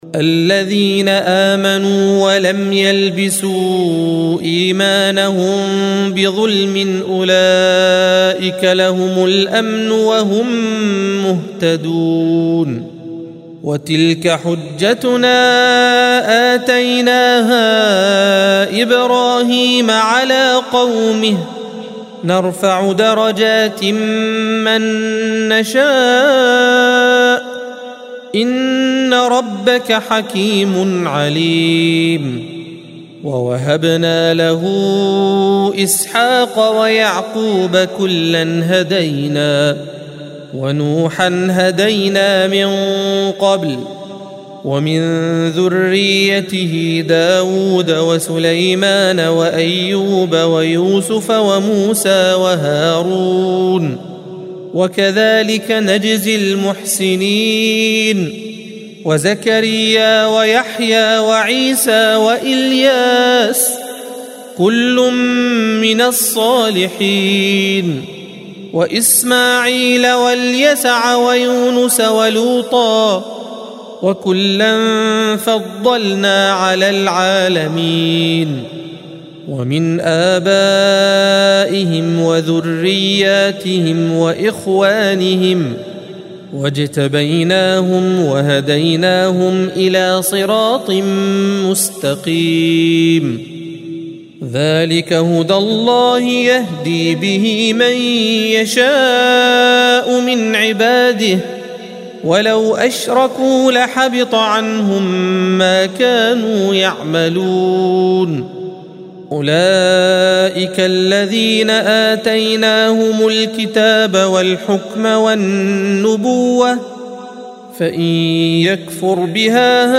الصفحة 138 - القارئ